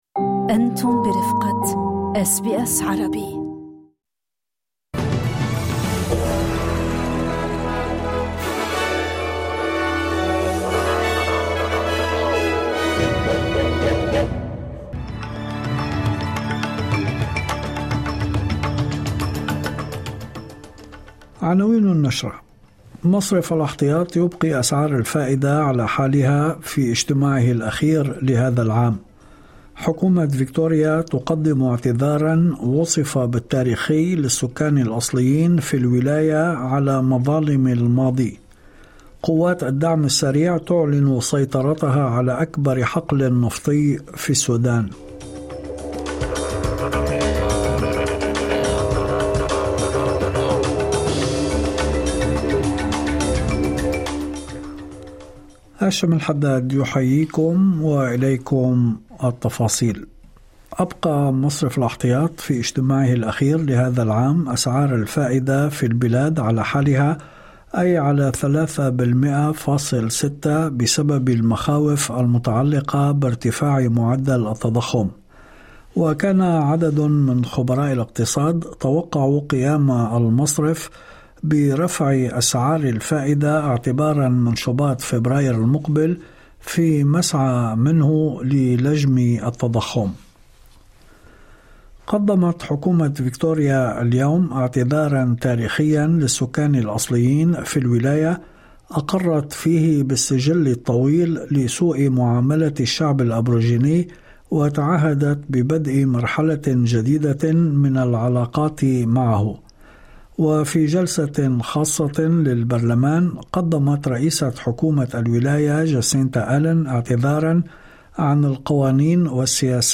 نشرة أخبار المساء 09/12/2025